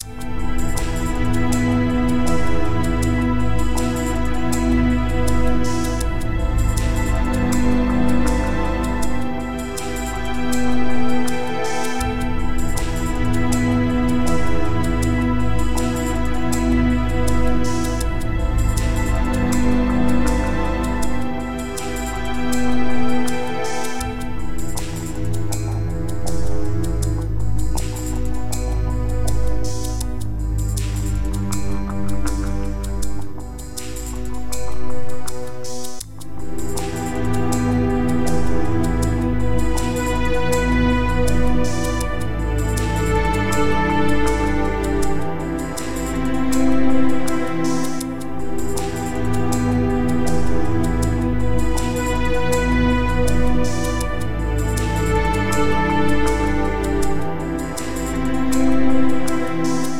dark fantasy escape game
Main Menu Theme MP3 Ending Theme MP3 Final Thesis PDF